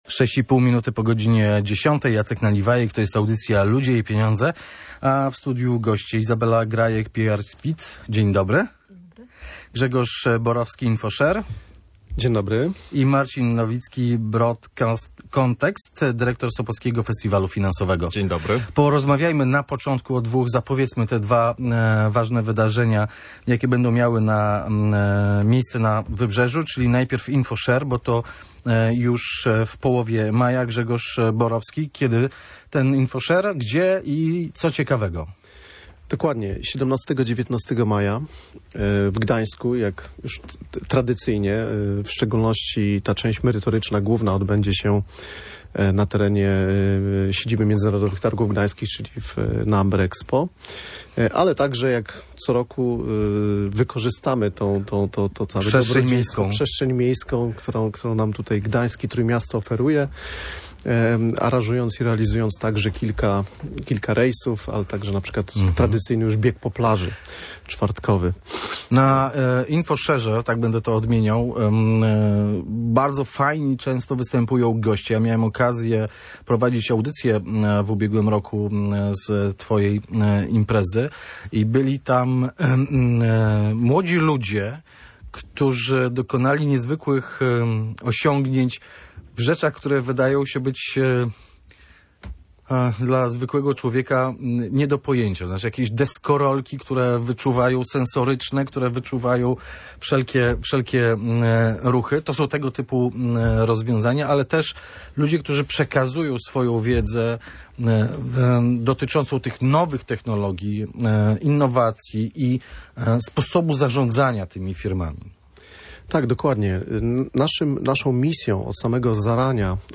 O zmianach na rynku pracy rozmawiali eksperci audycji Ludzie i pieniądze.